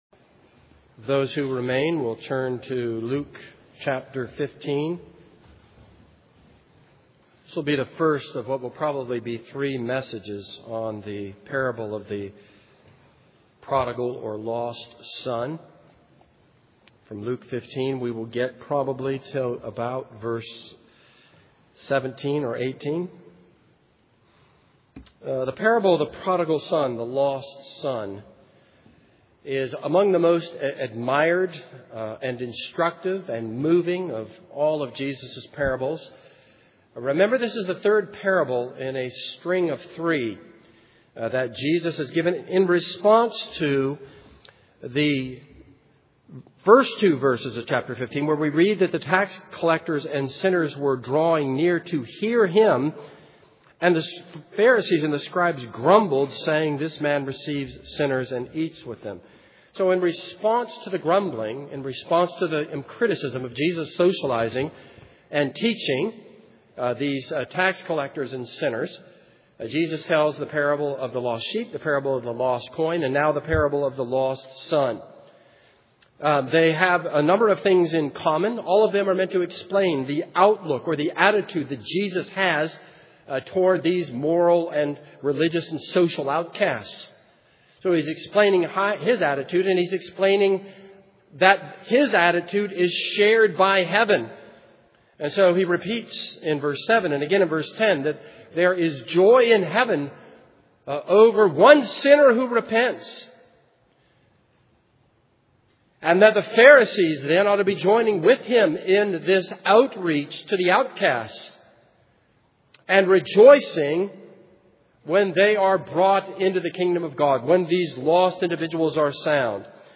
This is a sermon on Luke 15:11-32.